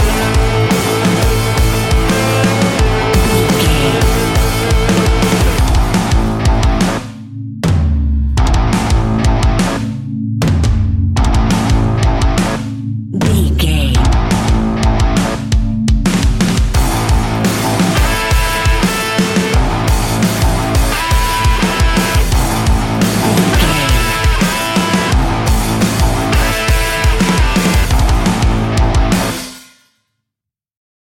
Ionian/Major
hard rock
heavy metal
instrumentals